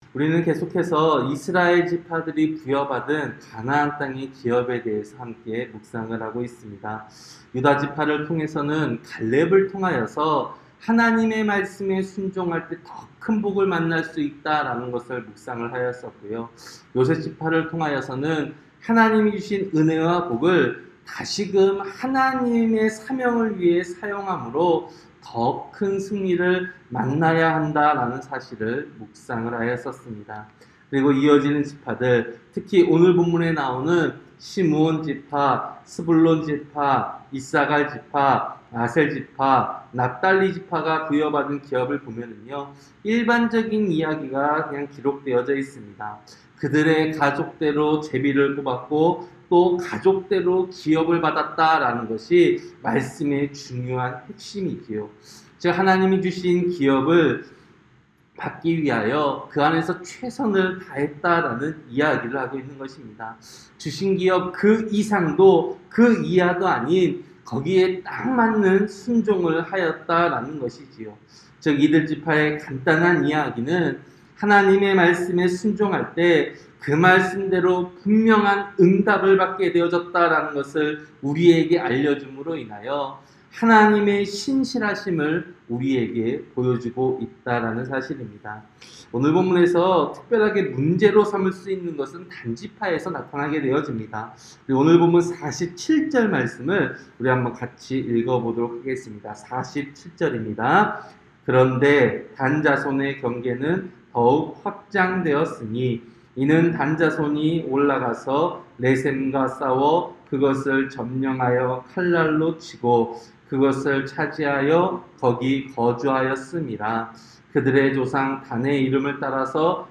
새벽설교-여호수아 19장